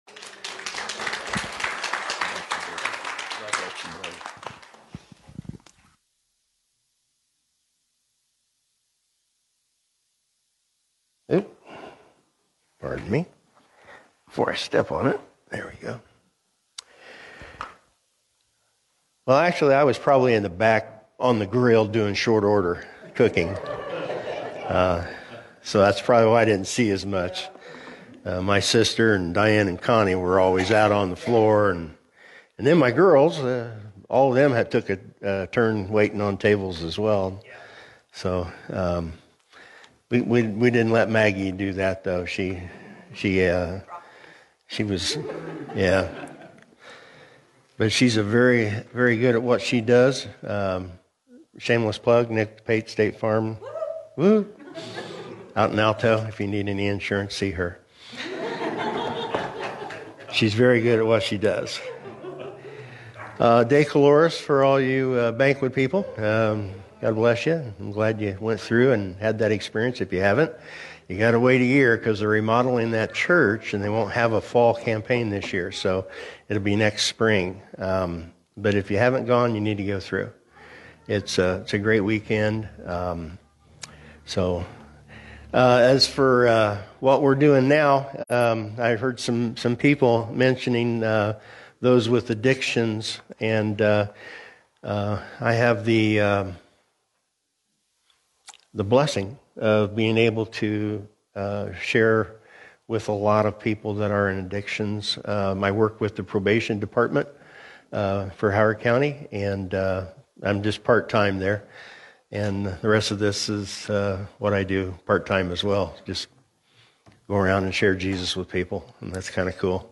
Sermons Archive - Here's Hope Church